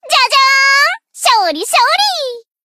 贡献 ） 分类:蔚蓝档案语音 协议:Copyright 您不可以覆盖此文件。
BA_V_Mutsuki_Battle_Victory_2.ogg